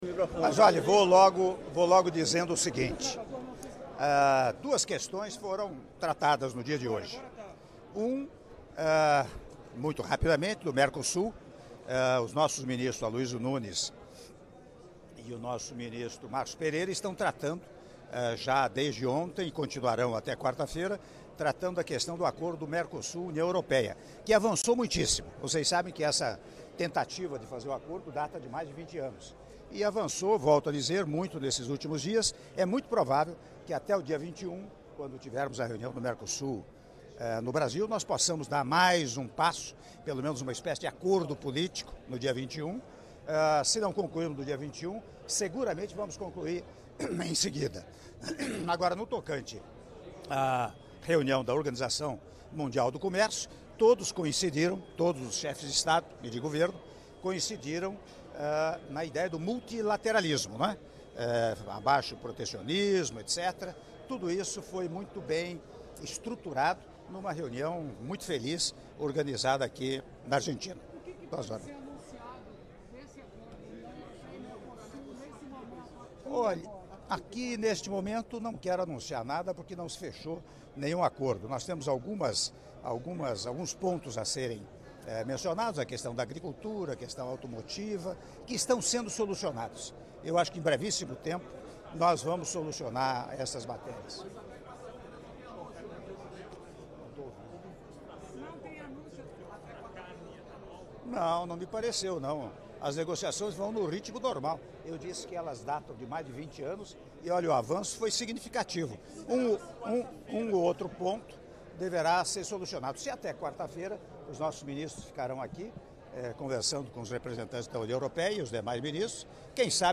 Áudio da entrevista coletiva concedida pelo Presidente da República, Michel Temer, após sessão de abertura da XI Conferência Ministerial da OMC - Buenos Aires-Argentina (04min01s)